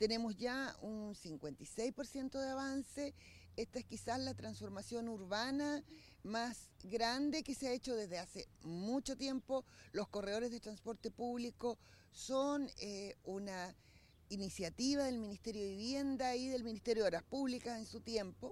La directora del Serviu Bío Bío, María Luz Gajardo, explicó que esta nueva matriz es clave para modernizar la red y asegurar el correcto desarrollo del proyecto vial.
cuna-directora-serviu.mp3